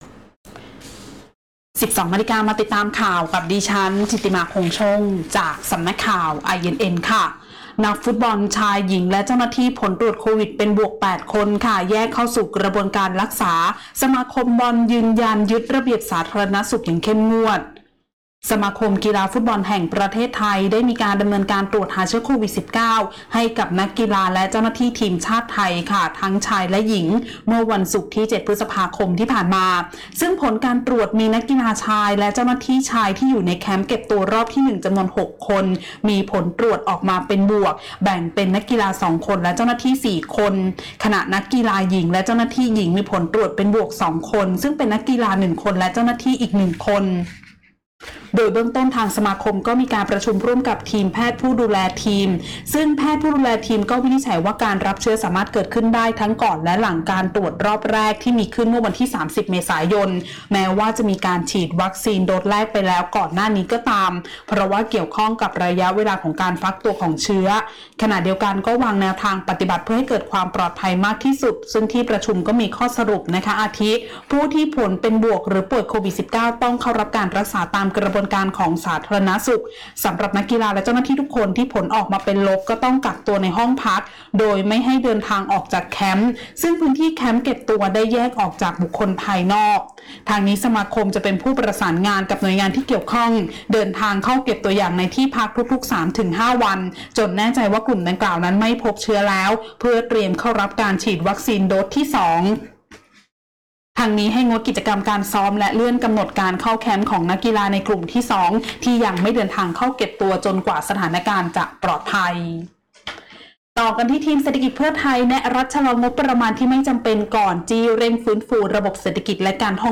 ข่าวต้นชั่วโมง 12.00 น.